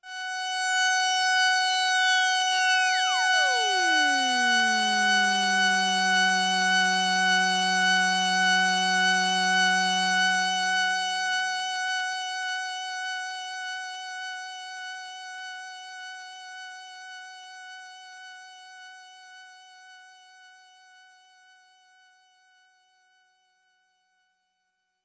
标签： F4 MIDI音符-66 挡泥板-色度北极星 合成器 单票据 多重采样
声道立体声